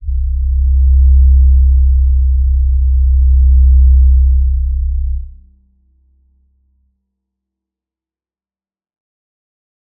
G_Crystal-C2-mf.wav